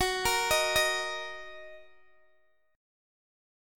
Listen to Gb6 strummed